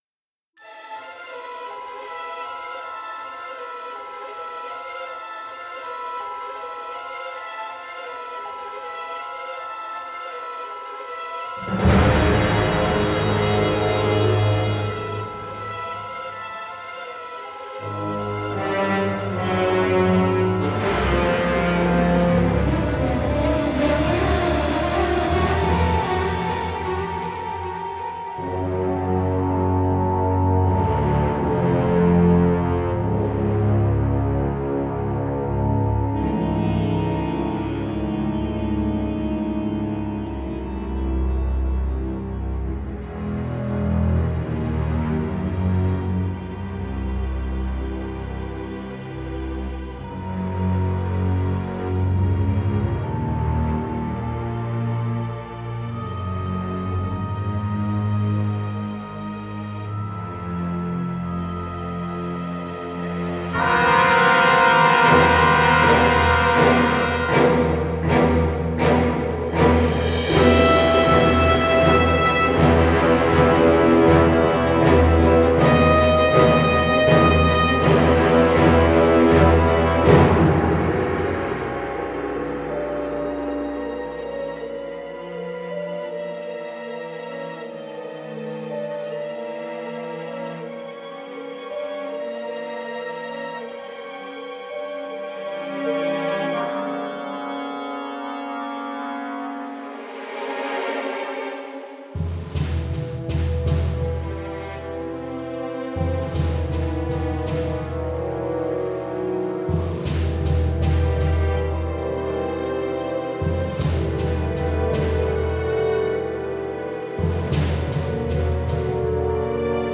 orchestral noir score